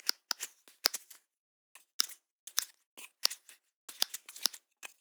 PlasticClicks_1_SG_2.wav